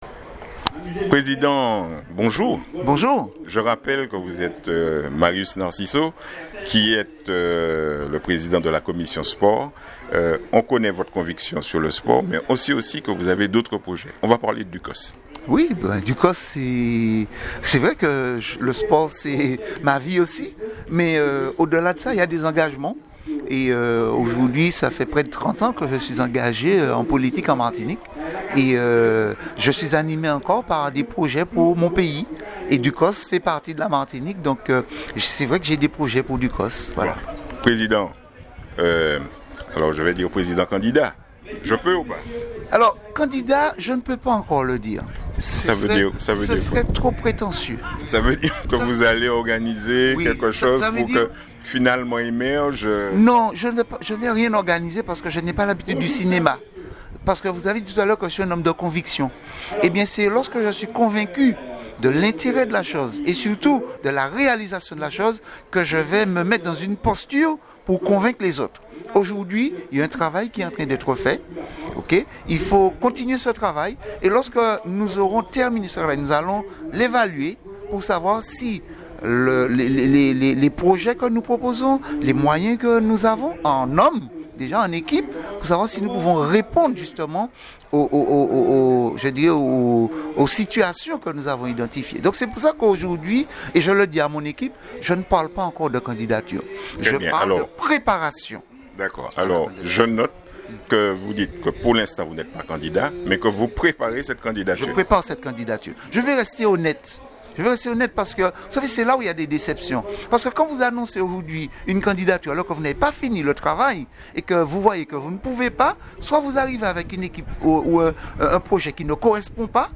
En écoutant ce reportage vous verrez bien que la campagne est belle et bien lancée.